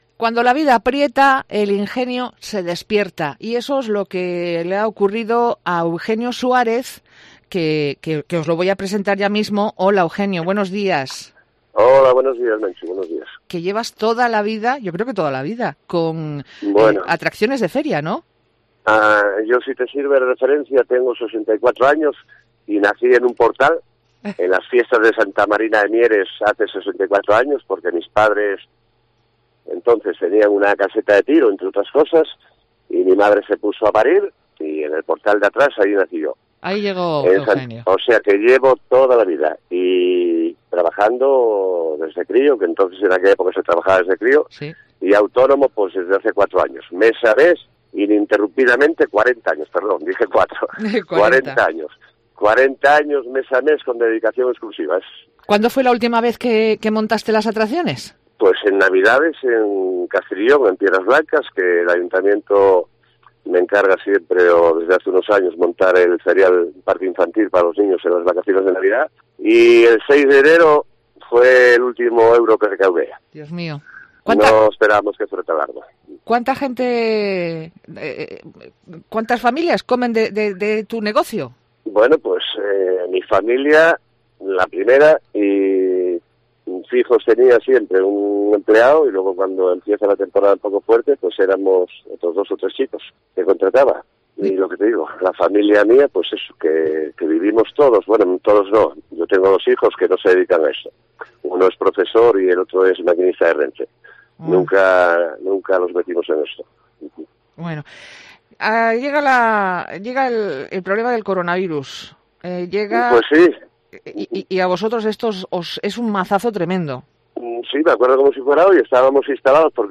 Mediodía COPE Asturias Entrevista